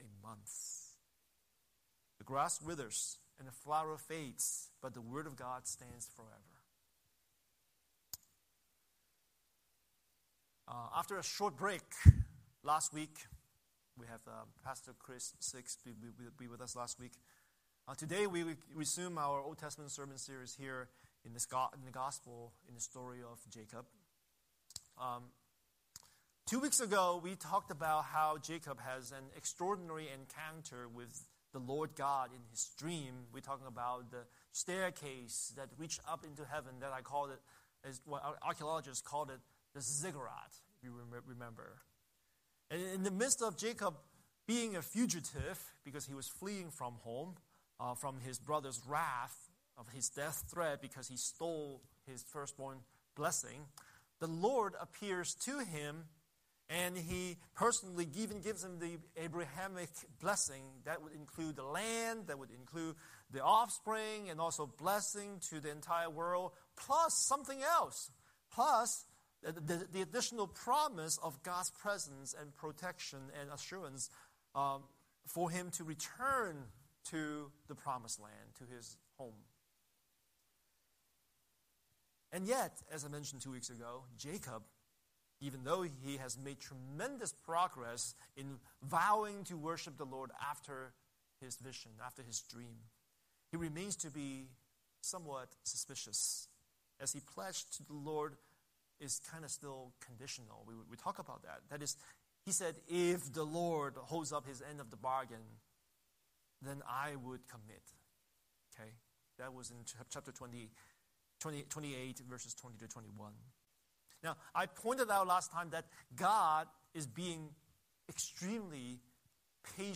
Scripture: Genesis 29:1–14 Series: Sunday Sermon